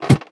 ladder4.ogg